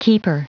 Prononciation du mot keeper en anglais (fichier audio)
Prononciation du mot : keeper